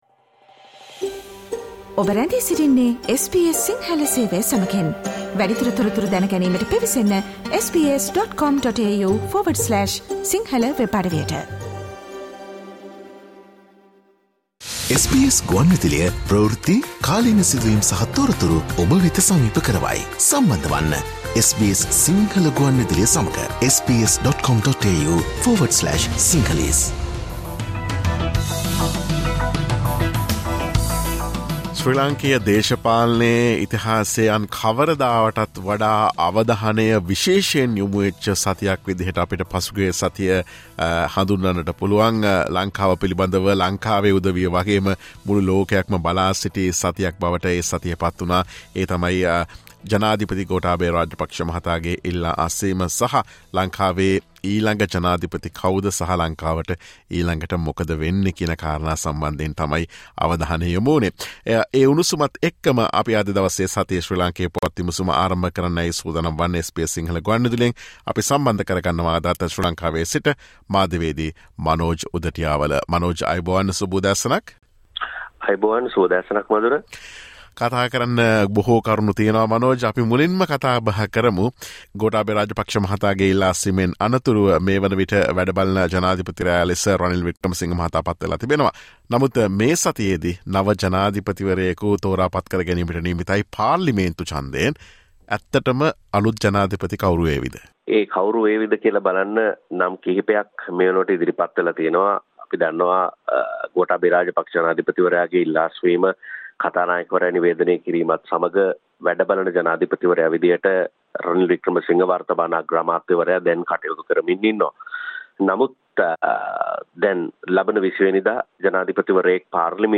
SBS සිංහල වැඩසටහන